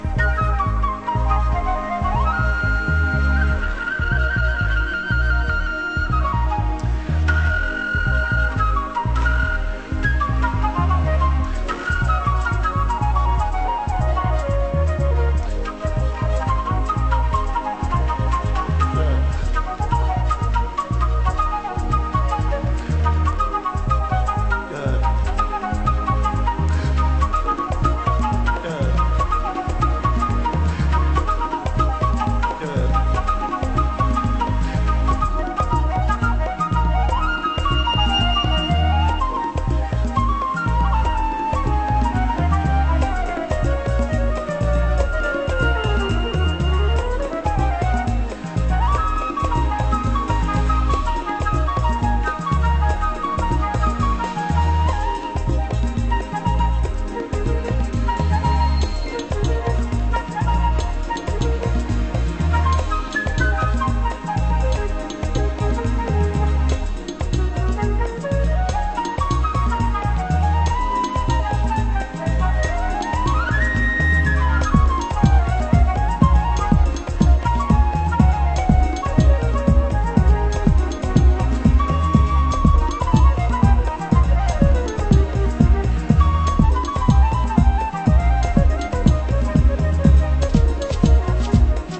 Flute Star Version